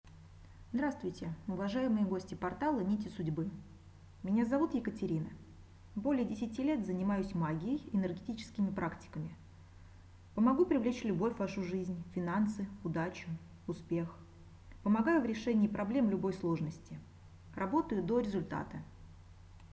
Аудио-приветствие